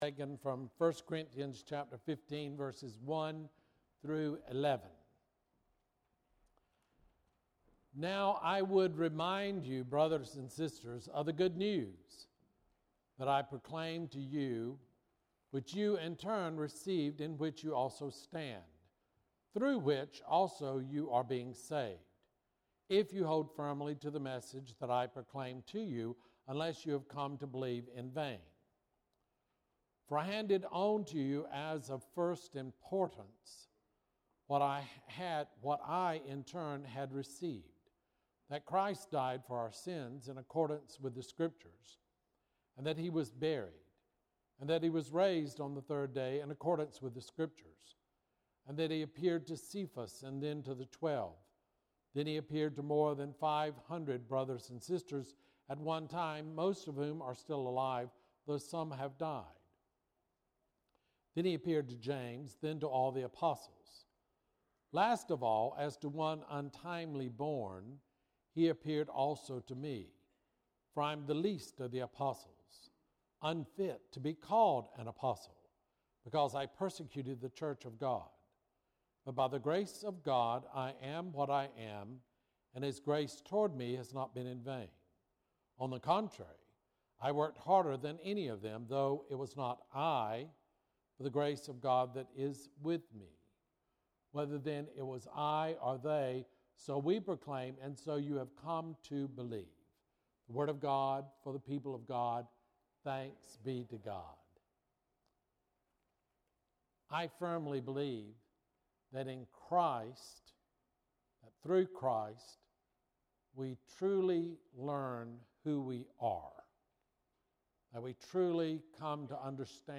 Bible Text: 1 Corinthians 15:1-11 | Preacher